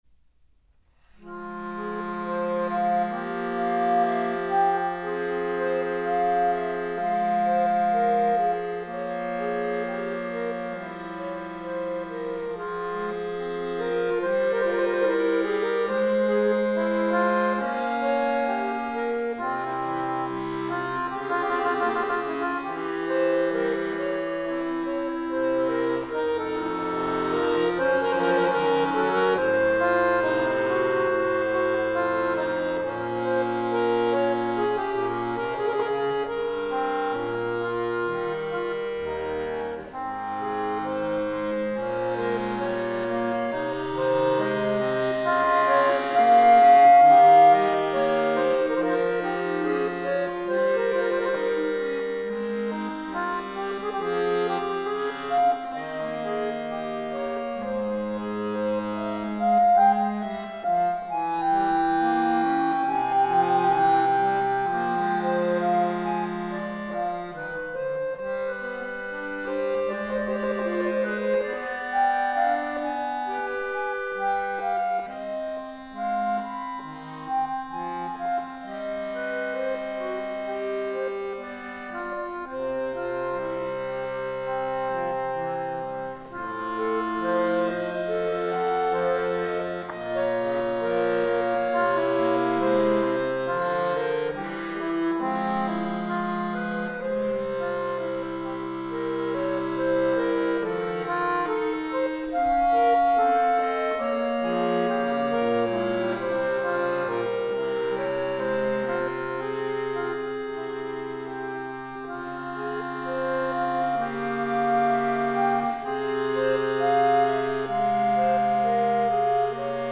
Akkordeon
Konzertsaal der Bundesakademie Trossingen
accordion